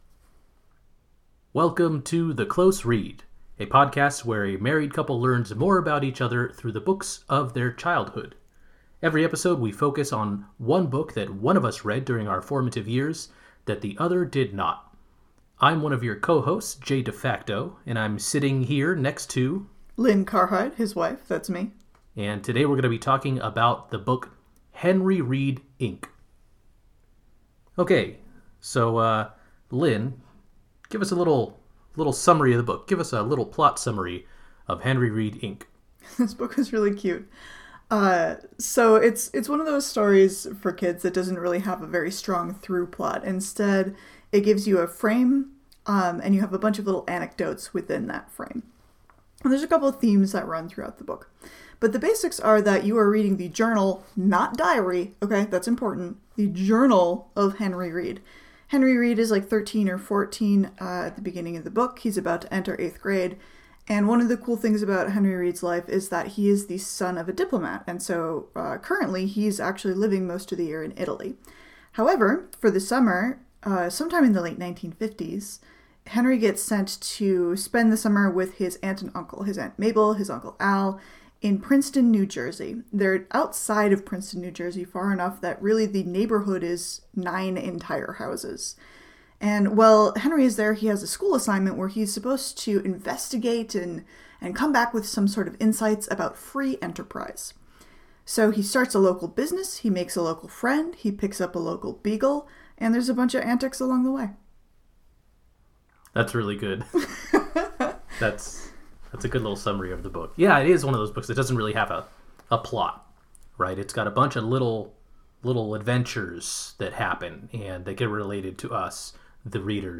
A podcast where a married couple learns more about each other through books we read. Every episode this season we focus on a book one of us read during their formative years that the other did not.